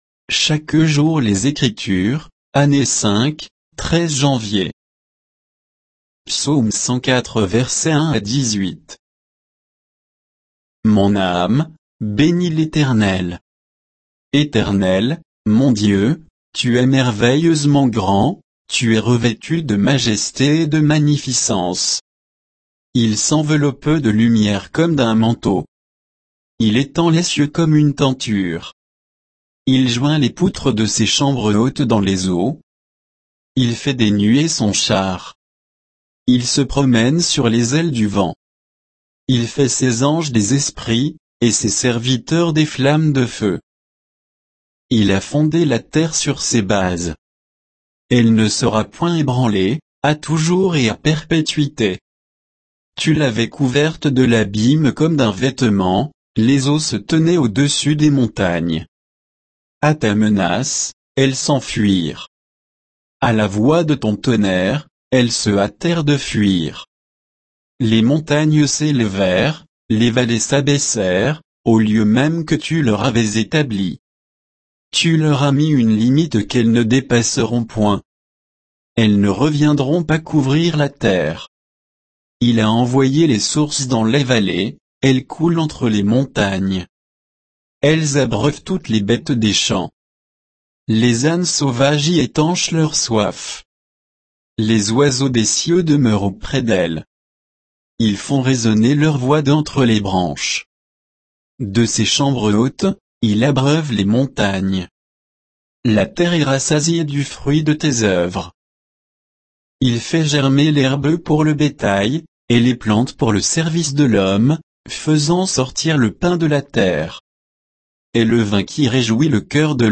Méditation quoditienne de Chaque jour les Écritures sur Psaume 104